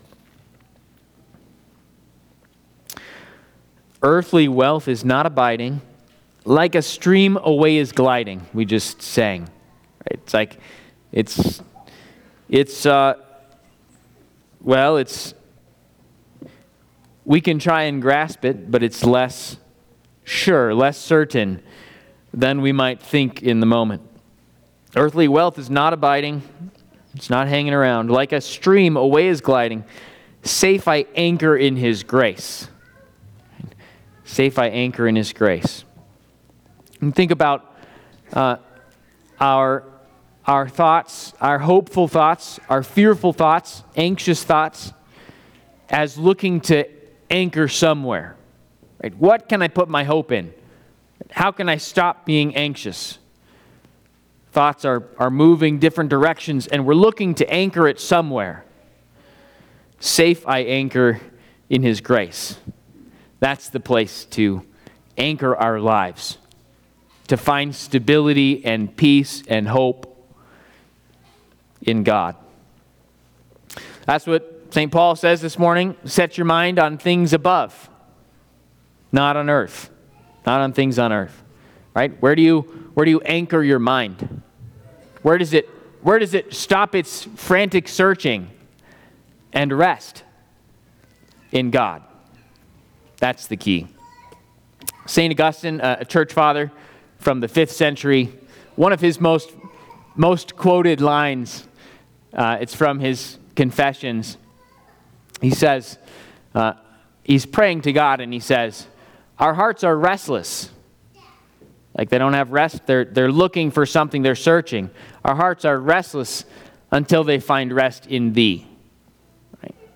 Eighth Sunday after Pentecost&nbsp